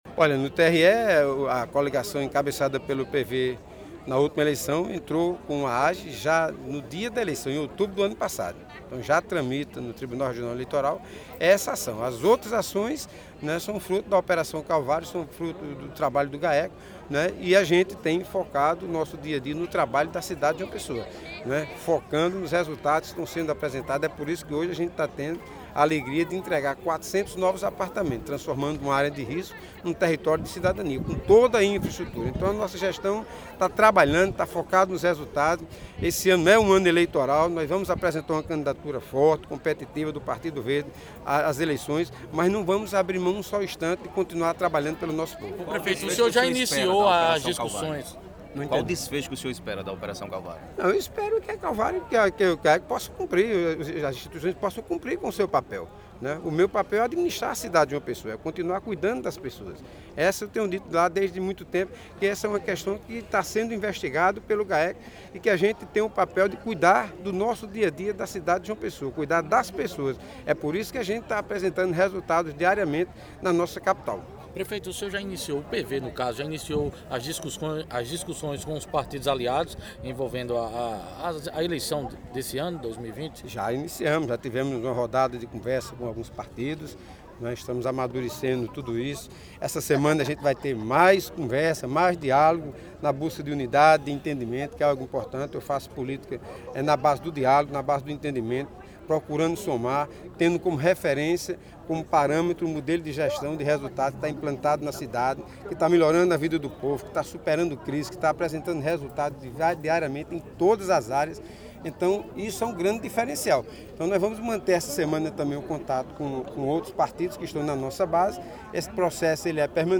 Ouça abaixo o áudio da entrevista com o prefeito.